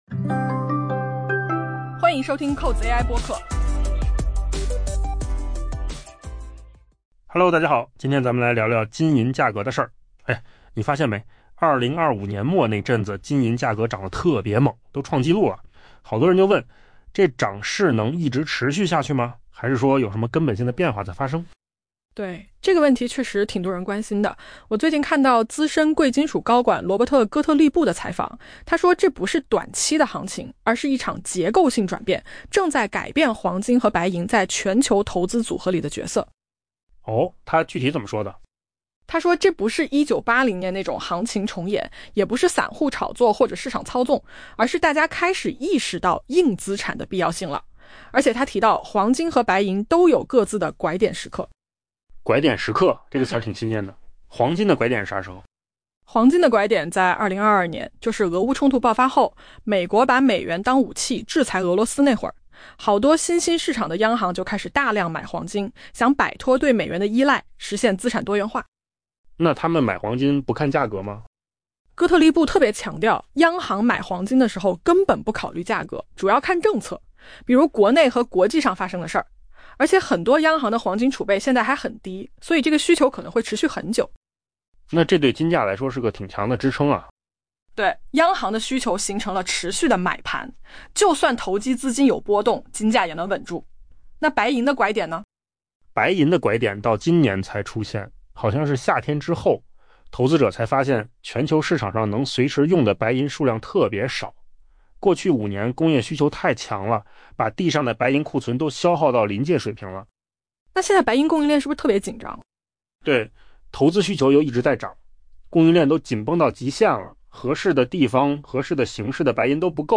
AI播客：换个方式听新闻 下载mp3
音频扣子空间生成